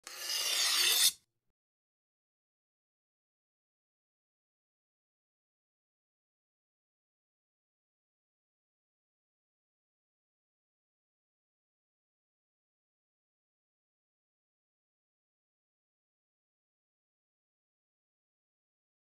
جلوه های صوتی
دانلود صدای تیز کردن ساطور از ساعد نیوز با لینک مستقیم و کیفیت بالا